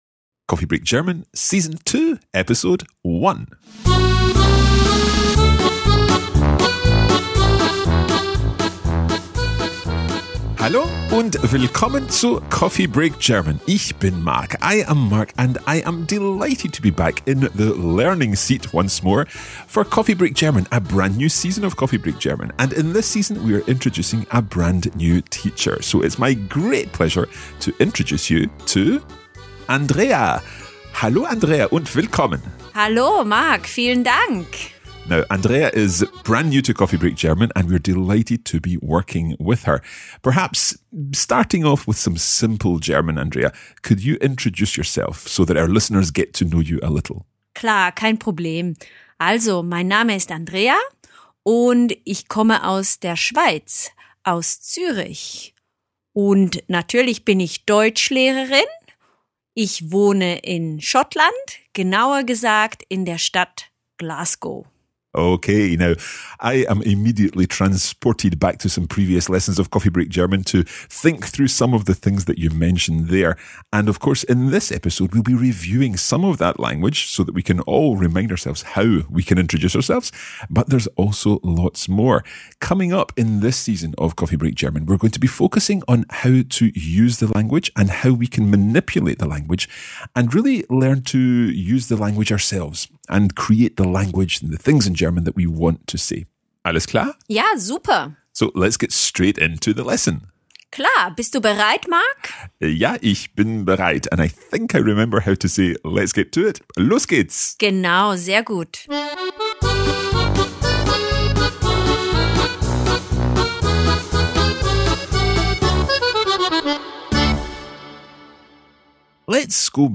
This is the audio version of the main lesson.